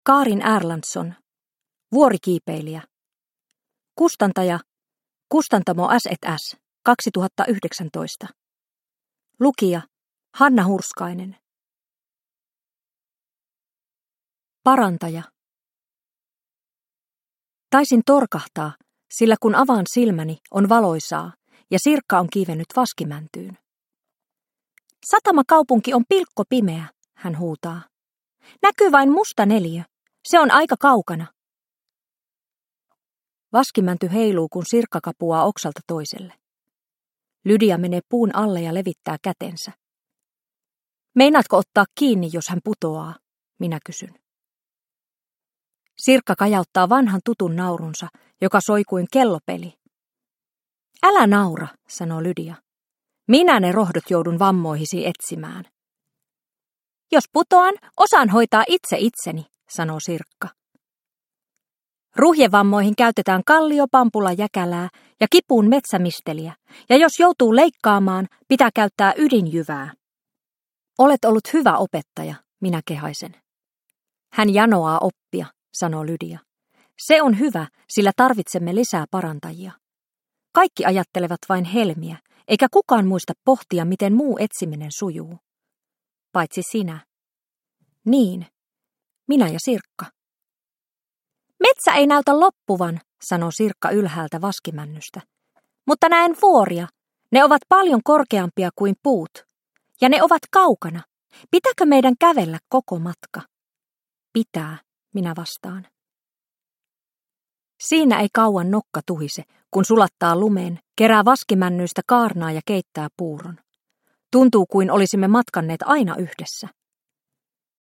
Vuorikiipeilijä – Ljudbok